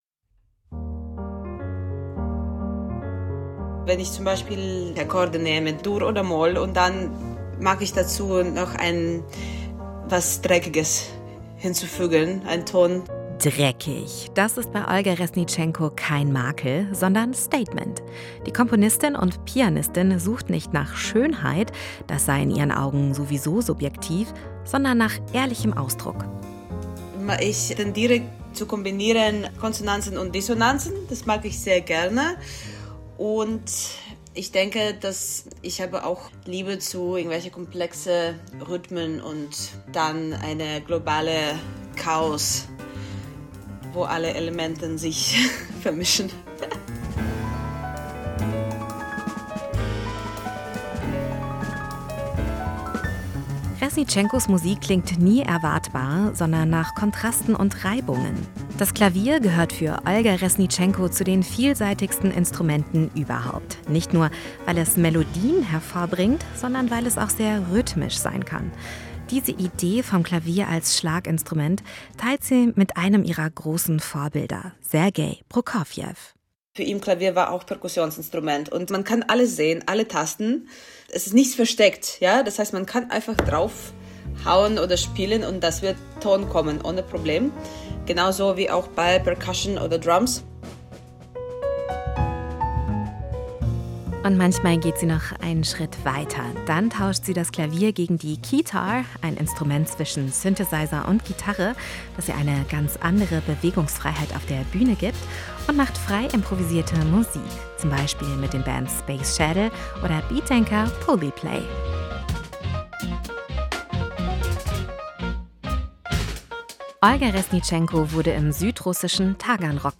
SWR Jazzpreis 2026 – Porträt